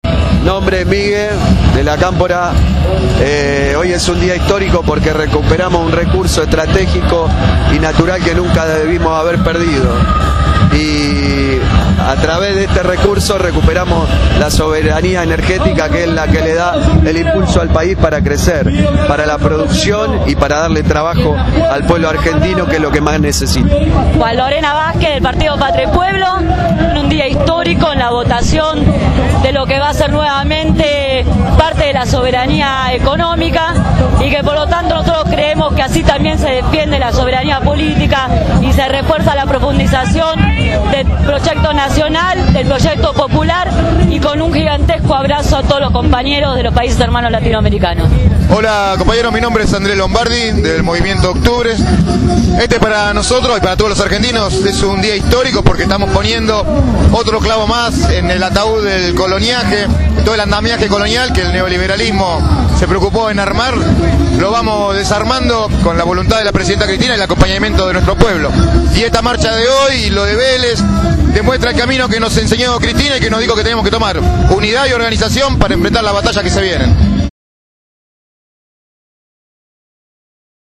En una jornada histórica, en la concentración frente al Congreso en las últimas horas antes de la votación, compañeros/as de diferentes fuerzas políticas y sindicales dejaron sus palabras en Radio Gráfica.